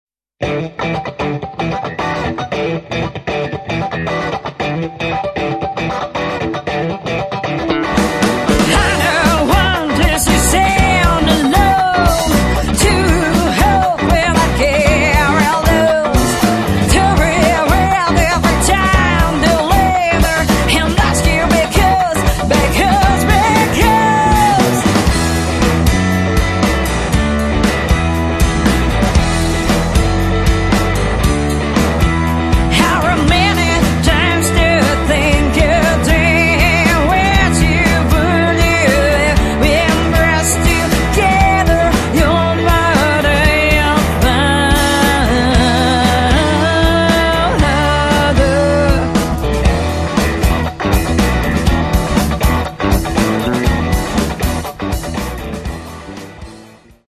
Blues / Rock / Funk